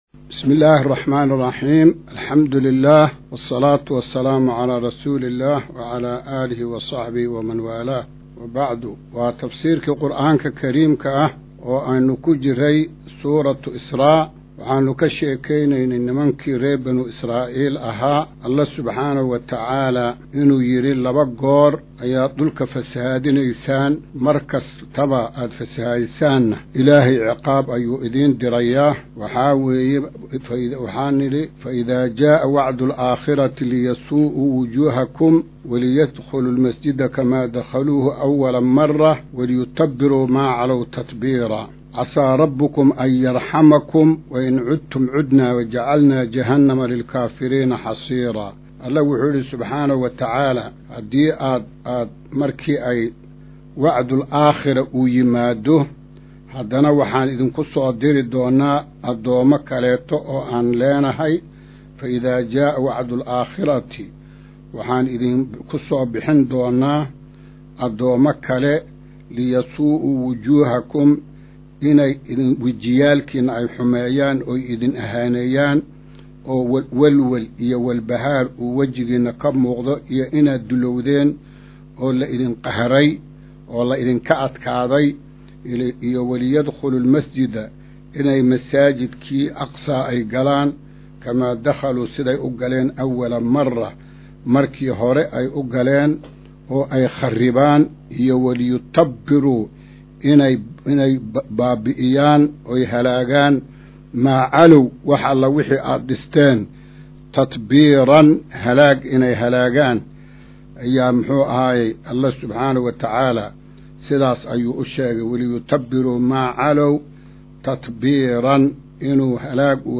Maqal:- Casharka Tafsiirka Qur’aanka Idaacadda Himilo “Darsiga 137aad”